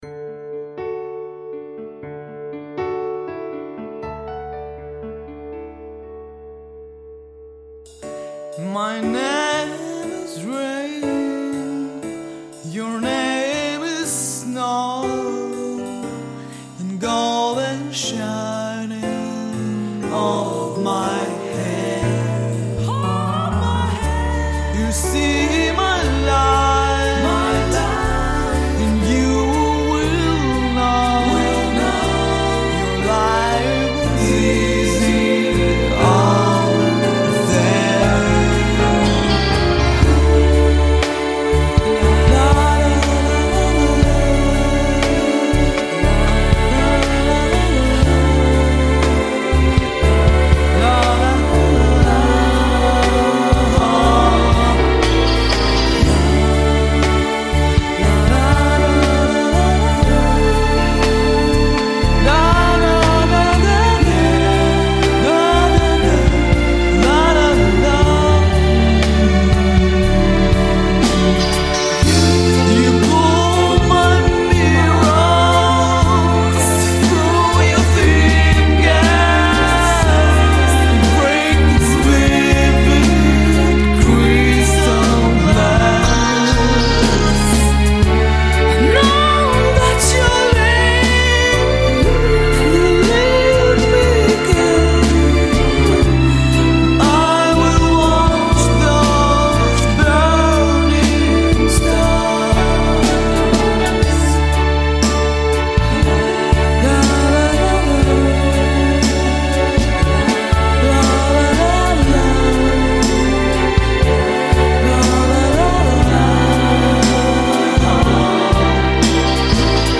Певческий голос Сопрано Меццо-сопрано